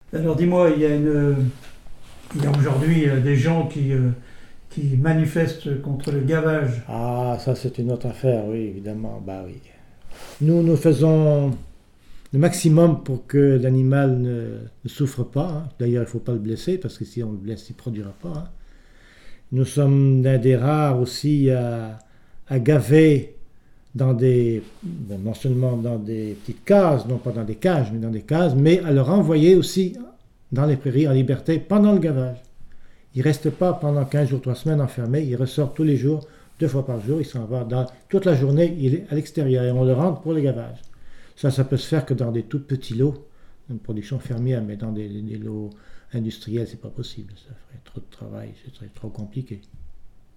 RADdO - Les précautions envers le gavage - Document n°232521 - Témoignage
Il provient de Saint-Gervais.